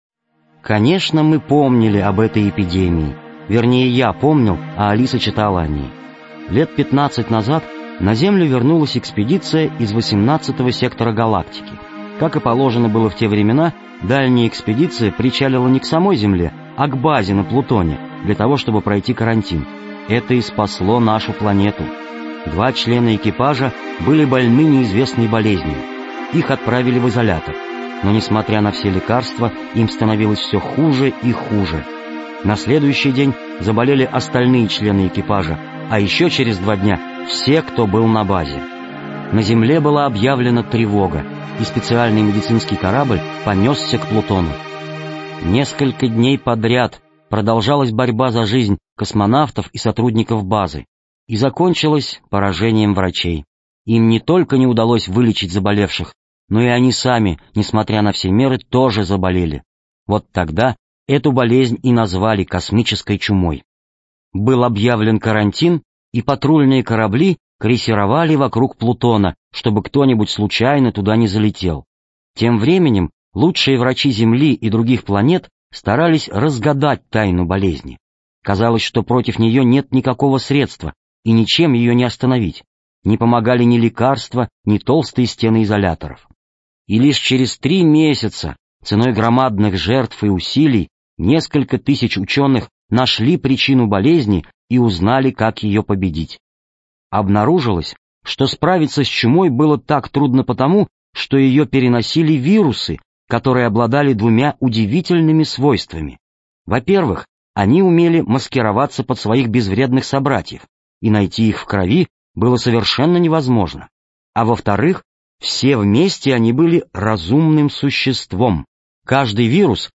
Аудио-сказка "День рождения Алисы" (скачать и слушать онлайн)